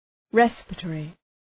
Shkrimi fonetik{‘respərə,tɔ:rı}
respiratory.mp3